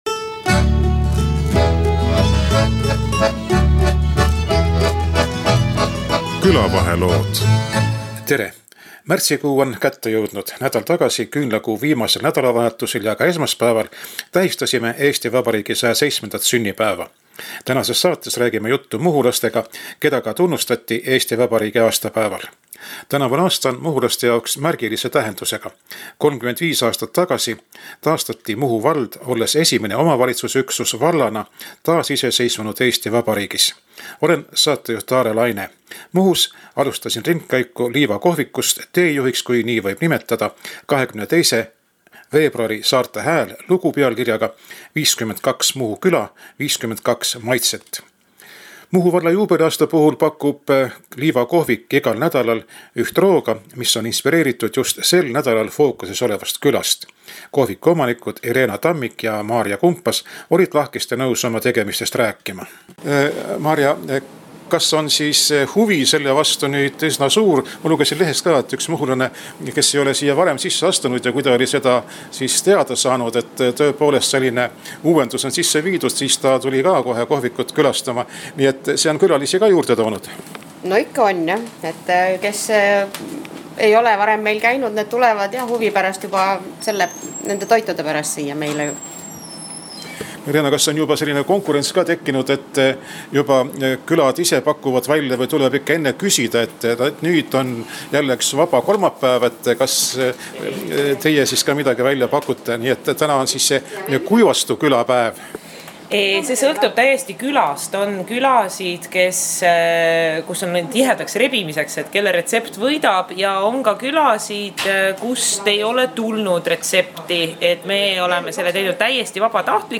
Liiva kohvikus